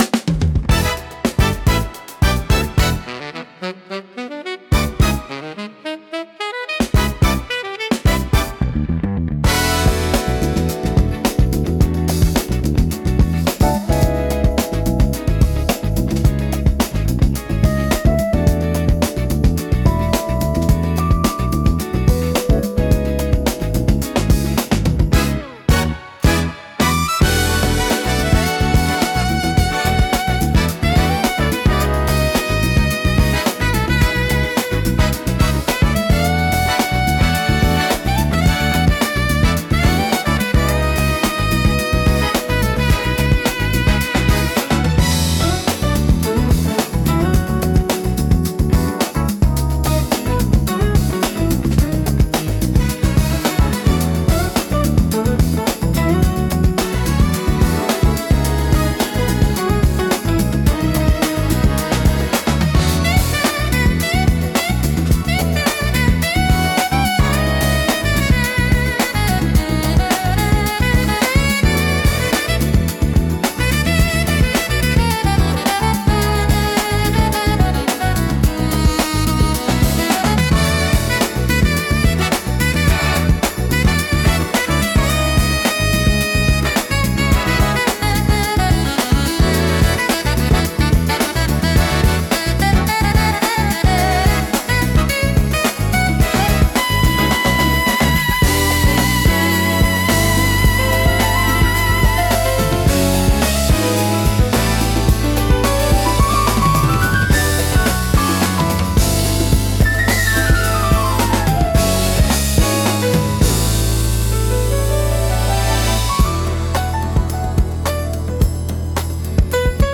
エレガントでリズミカルな演奏が心地よく、ダンスフロアからカフェシーンまで幅広く親しまれています。
落ち着きつつも躍動感があり、聴く人の気分を盛り上げつつリラックスさせる効果があります。